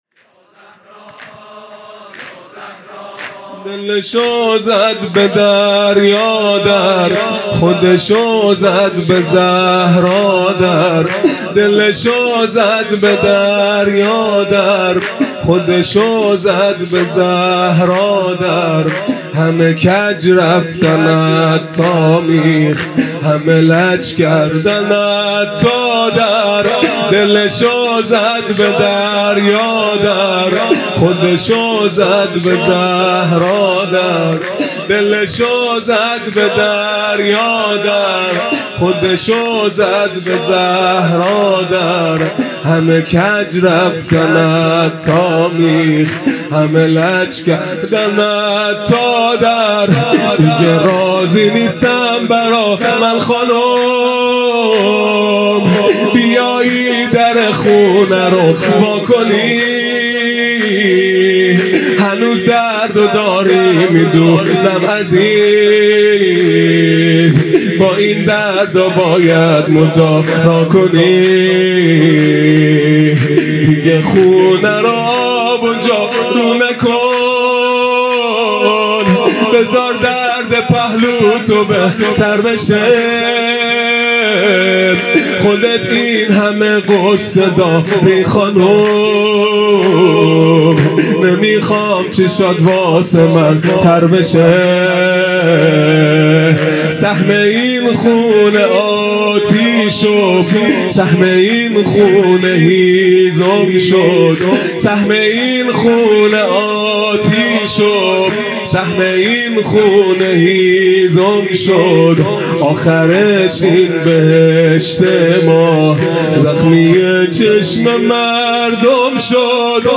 عزاداری فاطمیه اول | شب اول